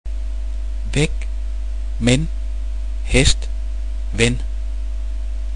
Danish Vowels
[æ] væk [væg] (away), men [mæn] (but), hest [hæsd] (horse), ven [væn] (friend)
læse [læ:sæ] (read, blæse [blæ:sæ] (blow), æble [æ:blæ] (apple), æg [æ'g] (egg(s))